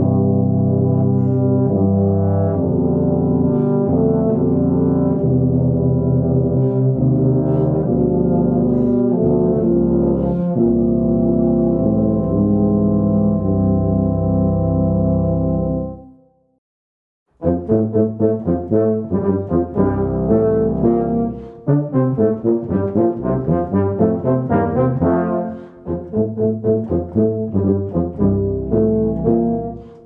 An intermediate tuba quartet arrangement